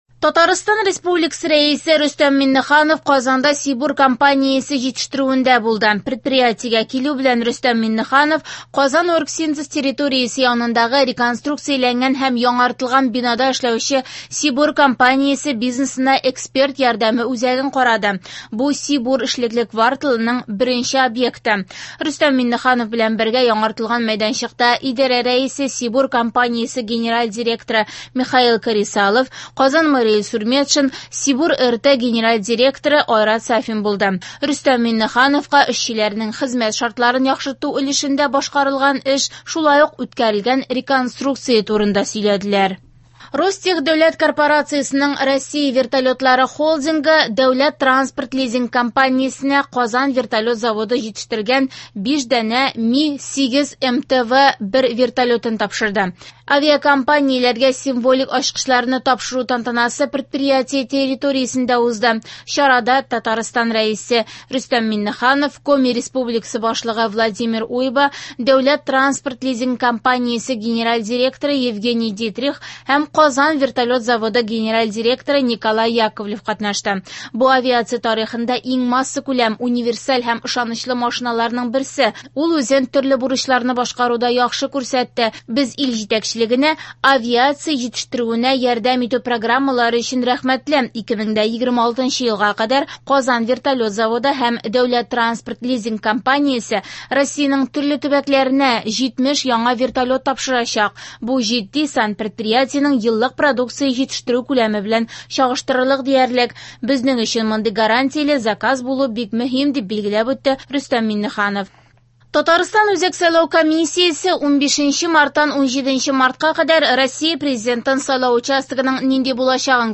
Яңалыклар (11.03.24)